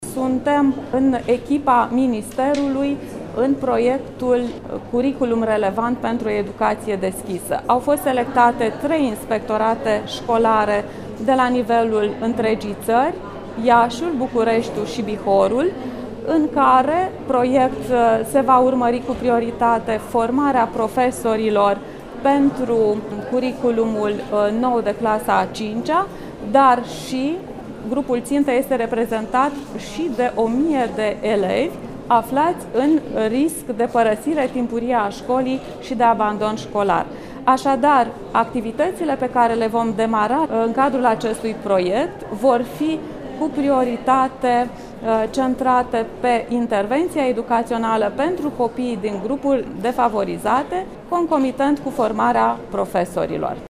Inspectorul școlar general al ISJ Iași, Genoveva Farcaș, a declarat că în acest proiect sunt implicate doar 3 inspectorate din țară și se va avea în vedere și situația abandonului școlar: